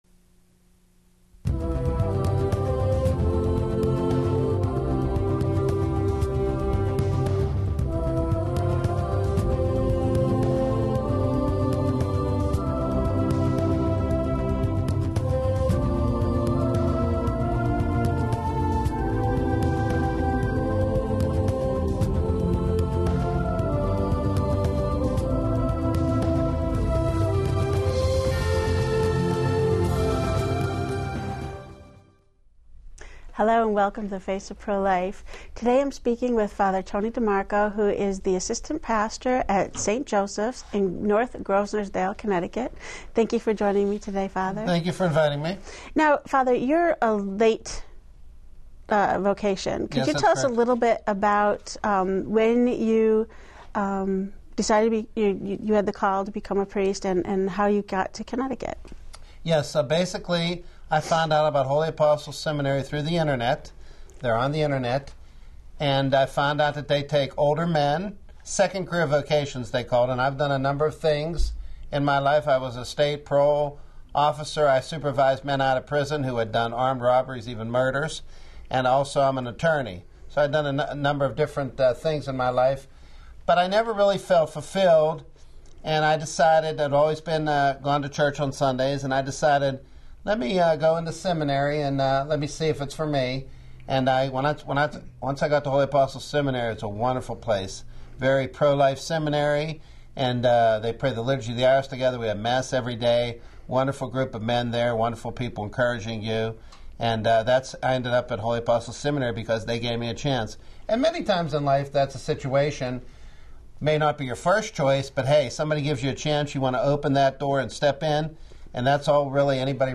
a Roman Catholic priest, speaks on his involvement in the pro-life movement before and after his priestly ordination.